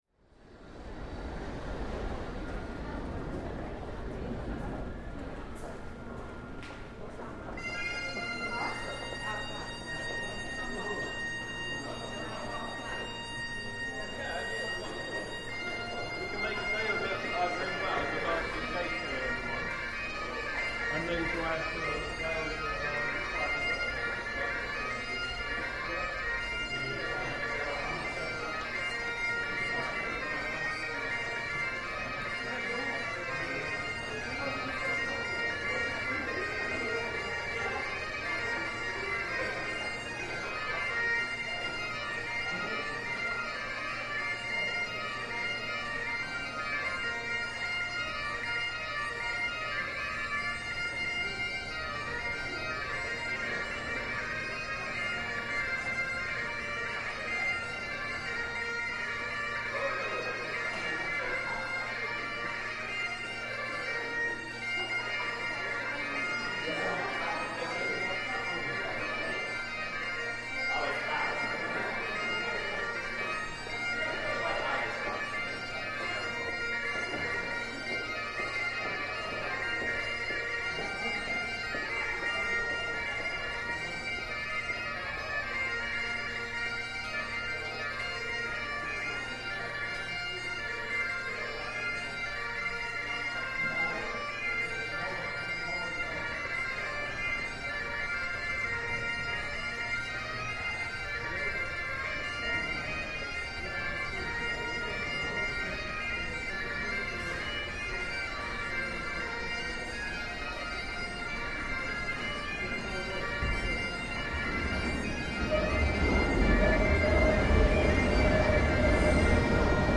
Bagpipe busker, King's Cross station
Field recording from the London Underground by London Sound Survey.